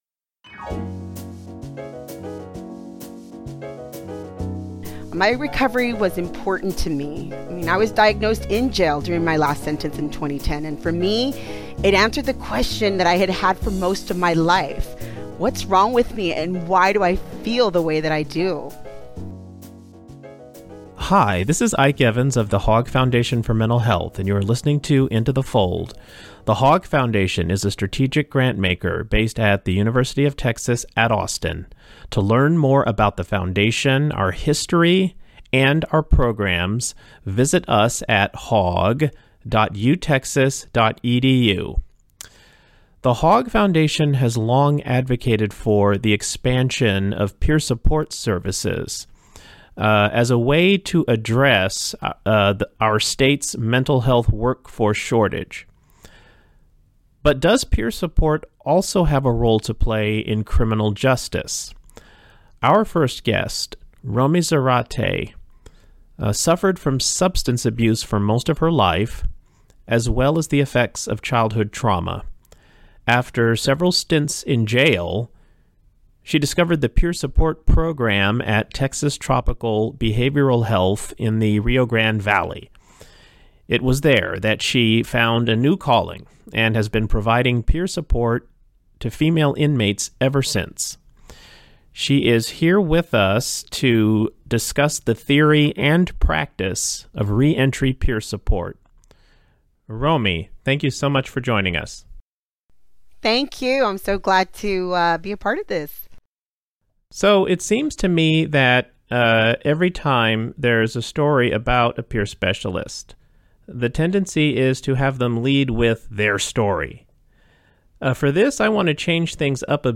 Peer support can make a huge difference. Learn about the new move toward peer support in the criminal justice system from two people whose own struggles now inform their work as peer supporters and advocates.